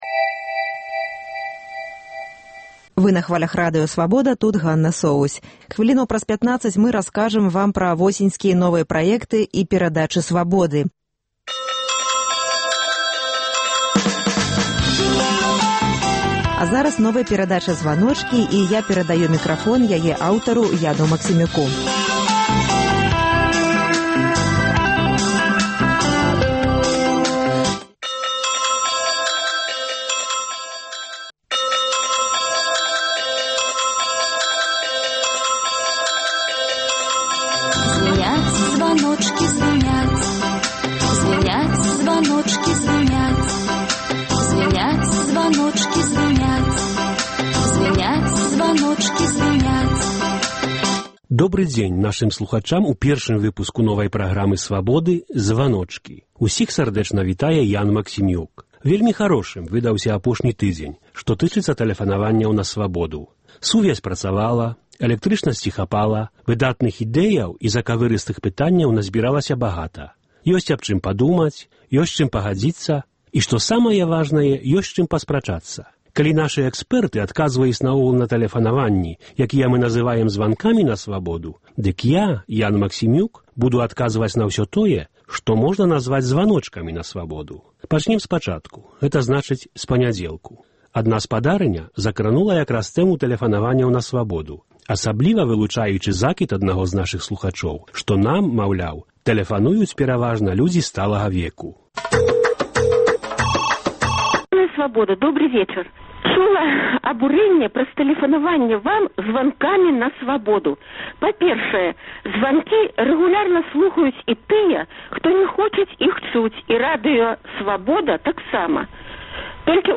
Гэта перадача — яшчэ адна магчымасьць для нашай рэдакцыі пагутарыць з нашымі слухачамі, уступіць зь імі ў адмысловую форму дыялёгу. “Званочкі” — гэта дыялёг з тымі слухачамі, якія звоняць на наш аўтаадказьнік у Менску і пакідаюць там свае думкі, прапановы і заўвагі.